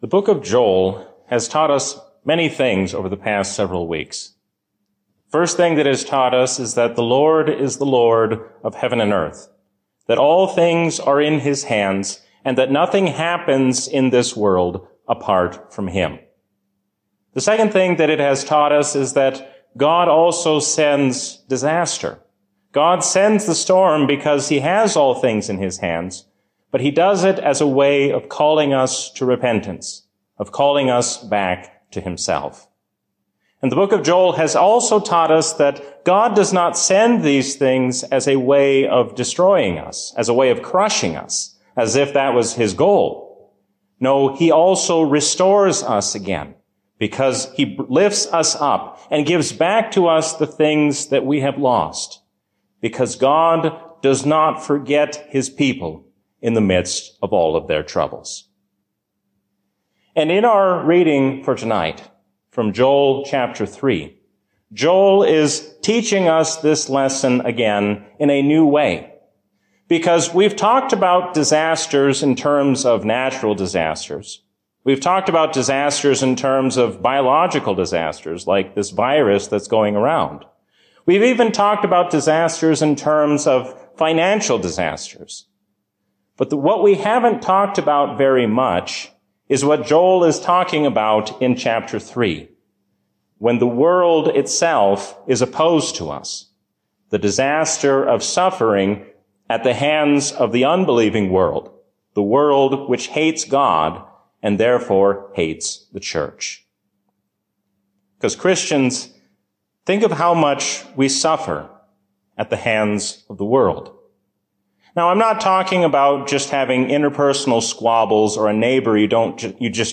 A sermon from the season "Trinity 2024." If God can forgive sinners like King Manasseh, He can also forgive you.